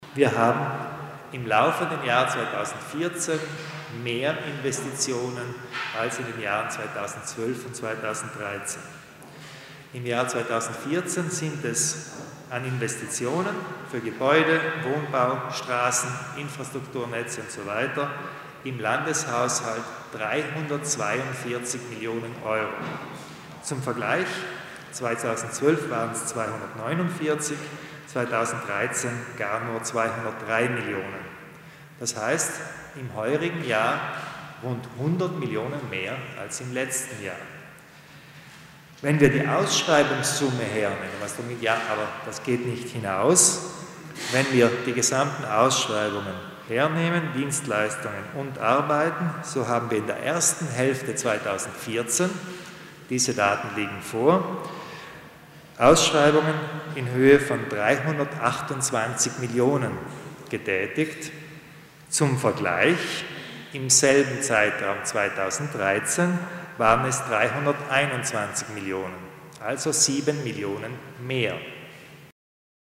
Landeshauptmann Kompatscher erläutert die Zahlen zu den öffentlichen Investitionen